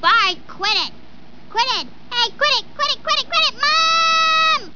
Esta es una lista con algunas voces que se oyeron en los episodios originales (en inglés) de los simpsons.